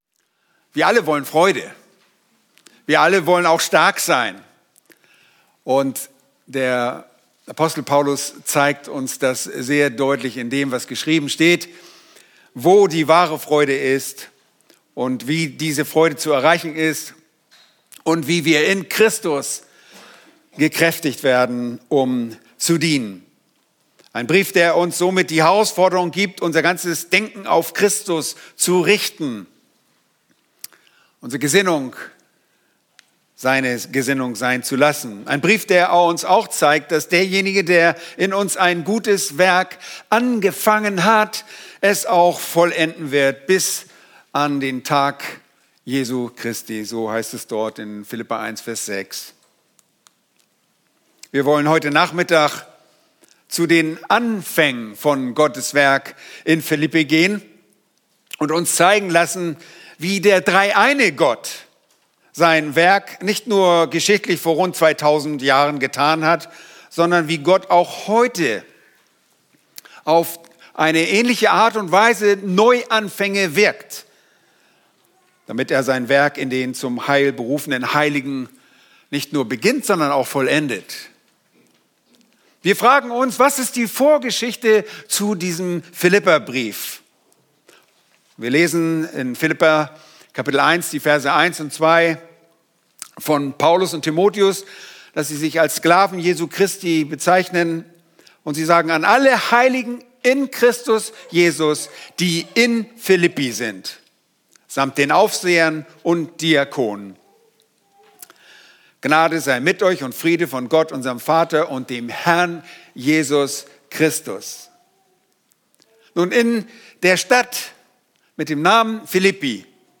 In dieser Predigt